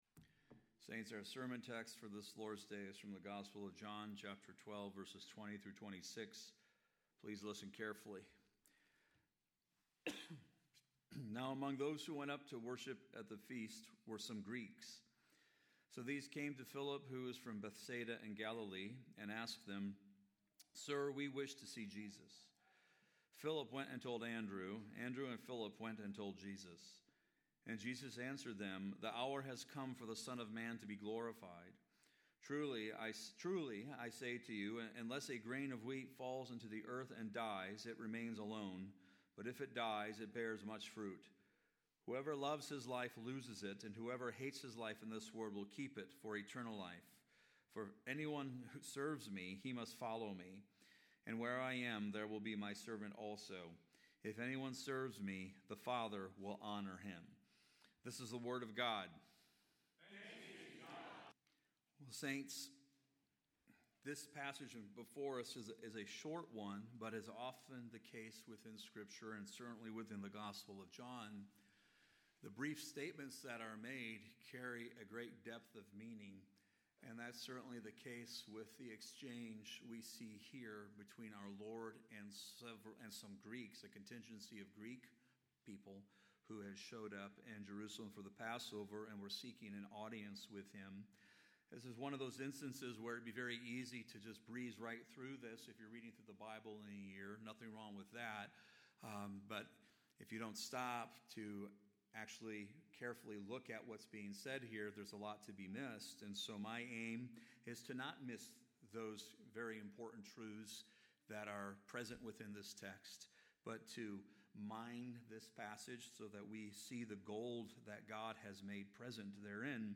TRC_Sermon-3.15.26.mp3